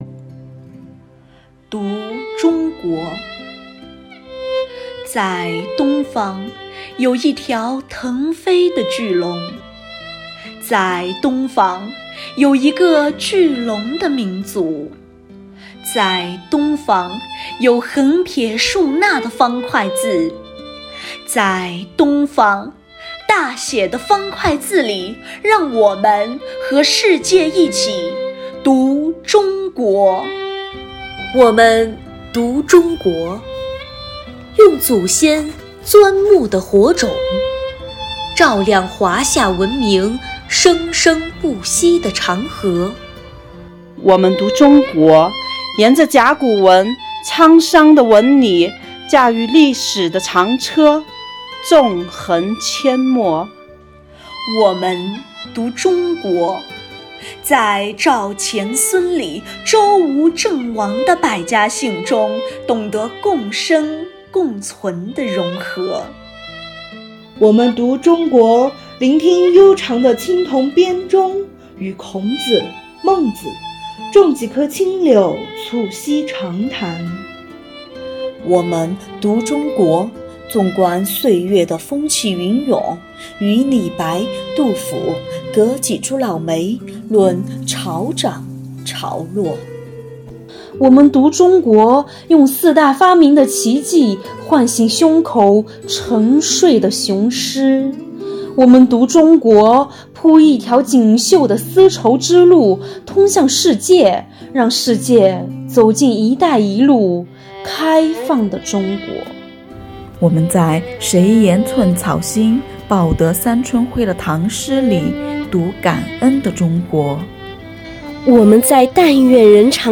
听！省第一女子监狱民警用最温情的咏诵表白祖国
省第一女子监狱11名民警接力朗诵《读中国》